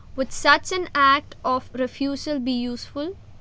Phonetically Rich Audio Visual (PRAV) corpus
a2302_F1.wav